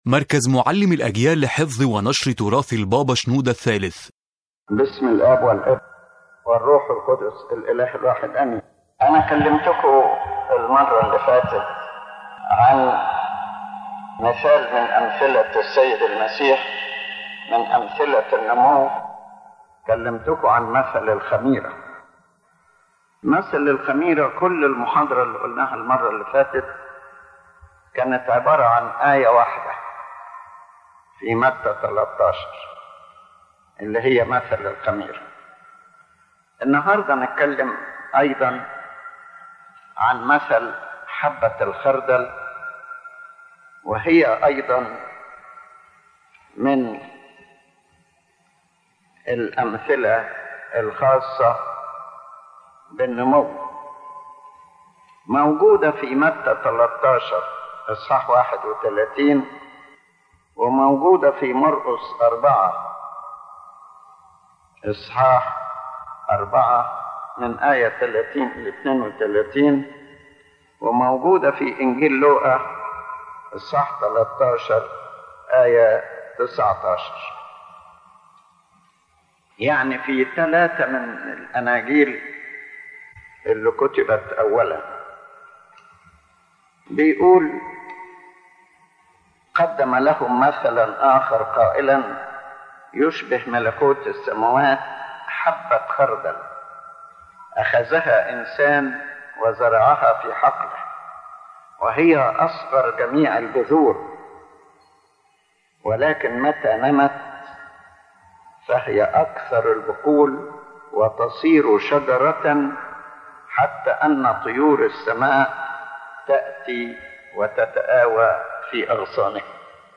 The lecture speaks about the parable of the mustard seed which the Lord Christ presented to explain the nature of the Kingdom of Heaven and how it begins small and then grows to become great. His Holiness Pope Shenouda III explains that the greatness in this parable is not in the smallness of the seed, but in its ability to grow until it becomes a great tree in which the birds of the sky dwell.